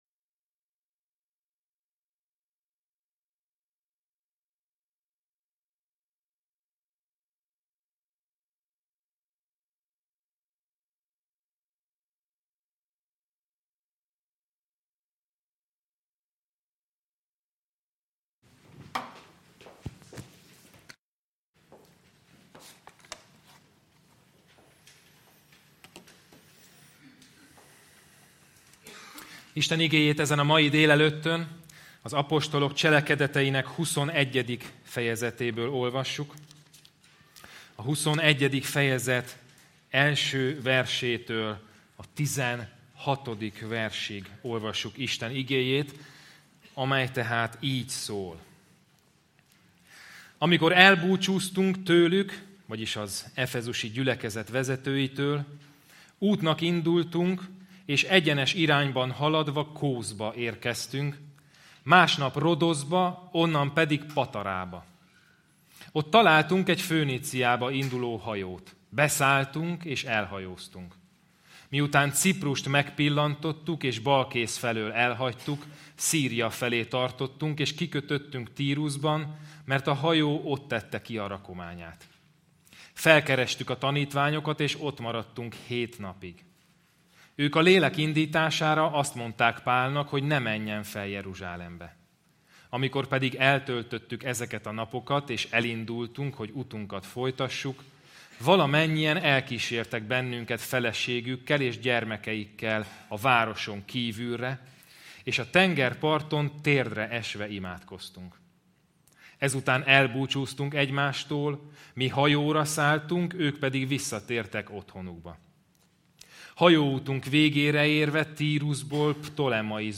Service Type: Igehirdetés